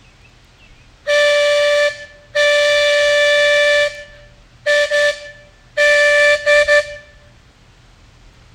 PRR GG1 Signal Line Whistle
This six inch whistle was found in the cab of the PRR GG1 electric.
gg1signalwhistle.m4a